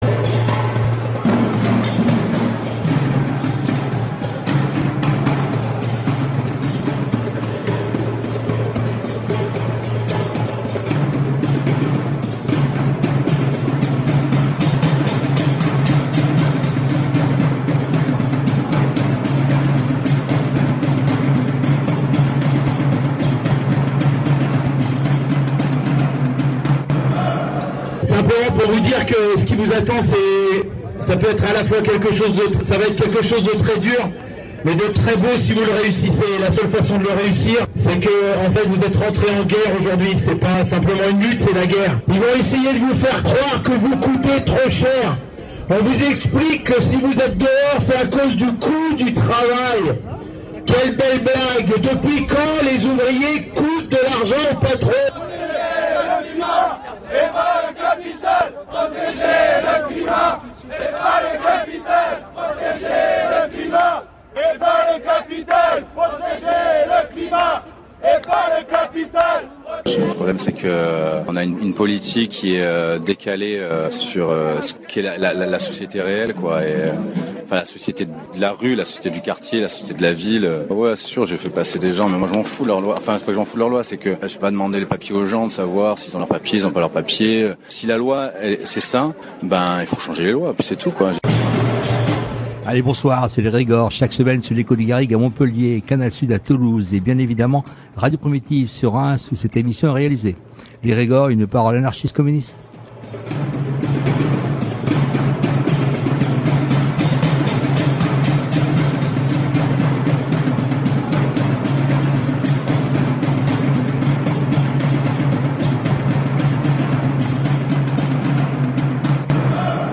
En avril dernier, à Gennevilliers, une rencontre de ses femmes et hommes qui ont travaillé pendant plusieurs années pour NTI-Environnement a lieu. Ils nous ont raconté leurs parcours et condition de travail dans cette société de traitement de déchets.